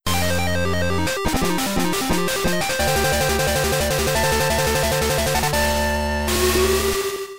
magnettrain.wav